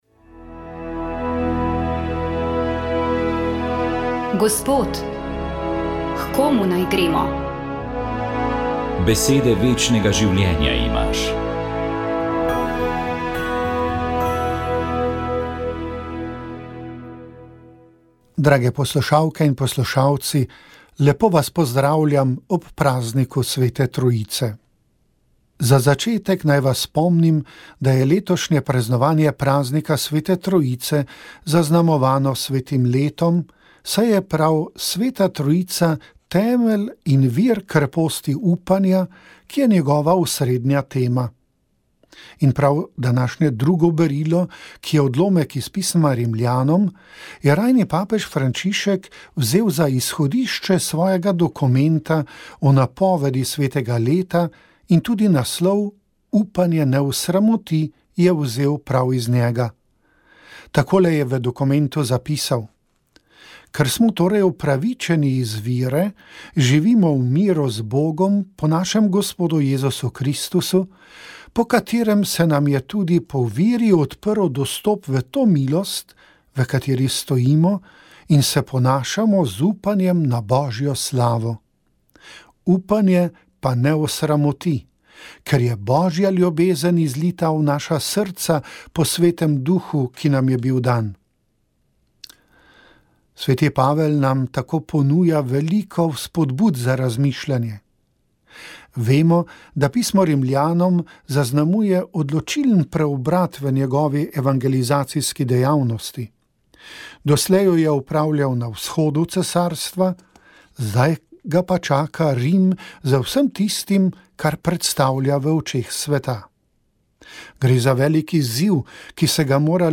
Vabljeni k poslušanju duhovnega nagovora na belo nedeljo.